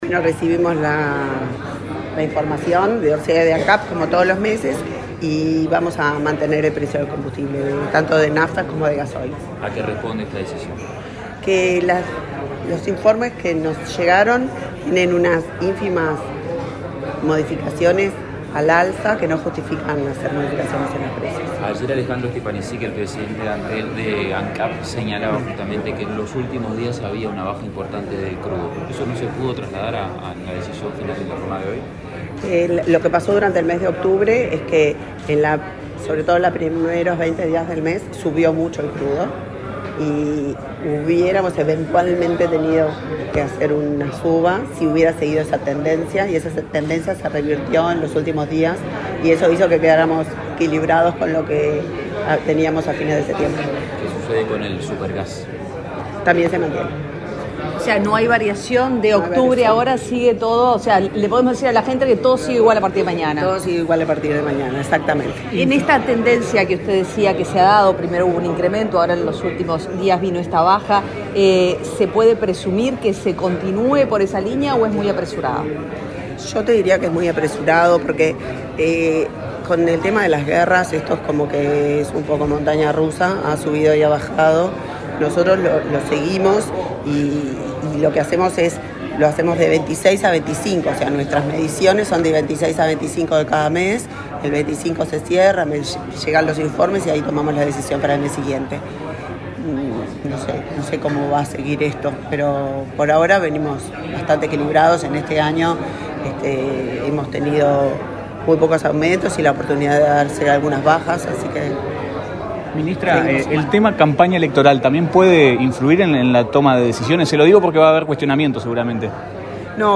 Declaraciones de la ministra de Industria, Elisa Facio